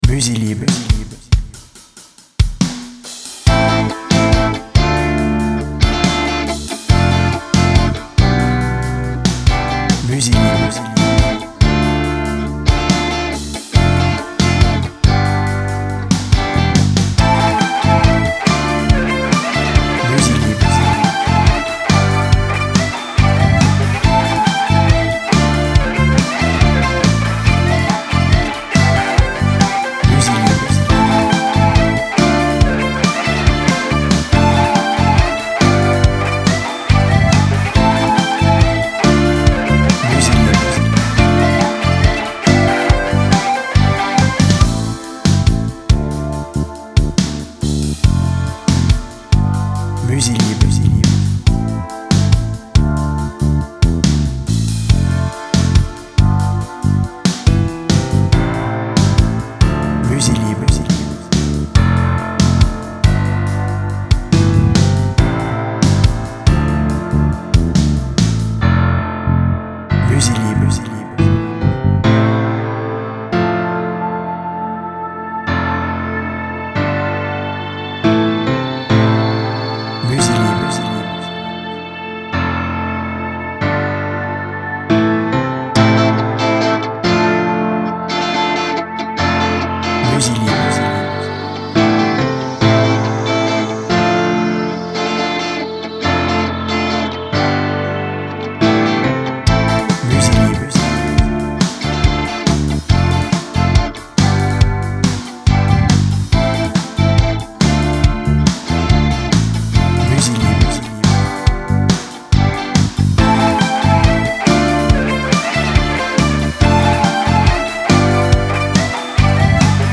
Une pop moderne, racée et très actuelle qui envoie du bois!
BPM Rapide